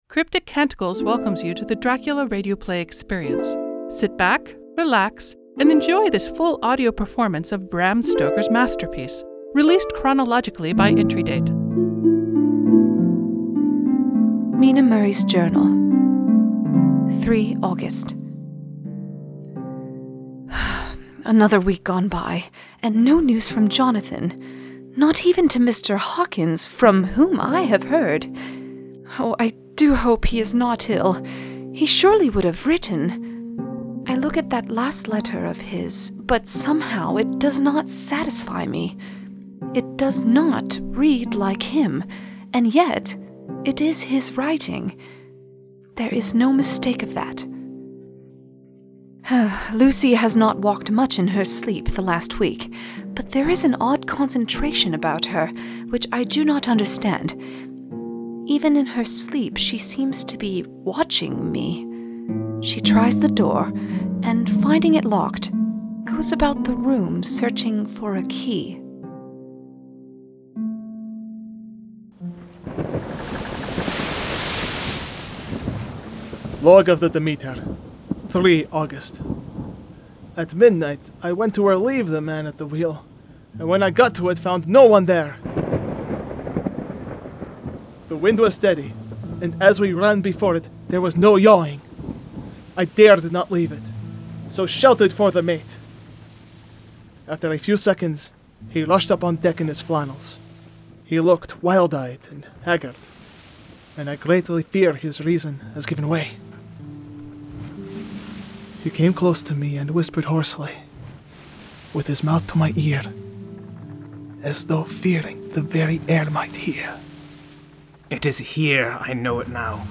This is a rebroadcast of the original 2017 work.